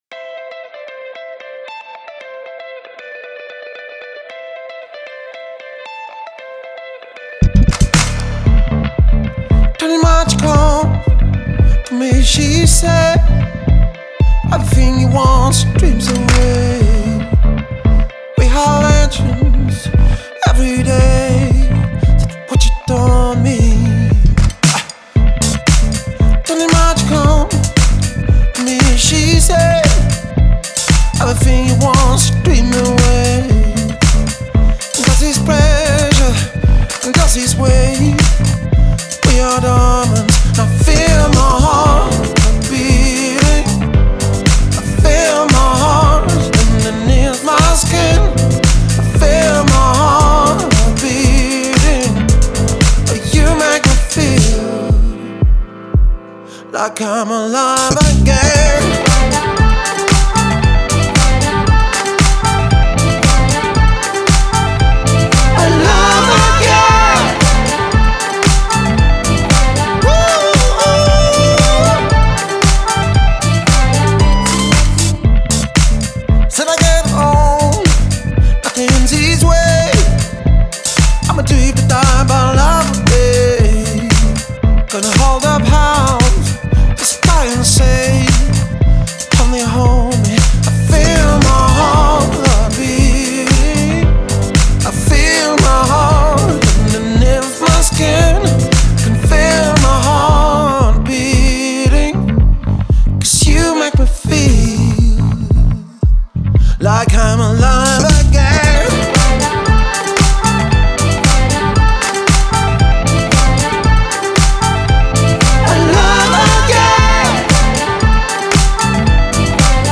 Five seasons - Orchestre live band Mariage -
L’association d’instruments acoustiques tels que les voix, les chœurs, le saxophone, les percussions, le piano, les claviers, la guitare et des sons électroniques actuels permet à Five seasons d’interpréter un répertoire large et complet qui s’adaptera à